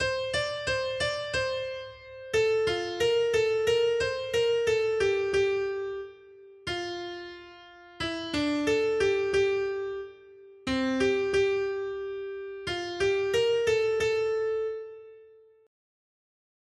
Noty Štítky, zpěvníky ol313.pdf responsoriální žalm Žaltář (Olejník) 313 Skrýt akordy R: Na věky chci zpívat o Hospodinových milostech. 1.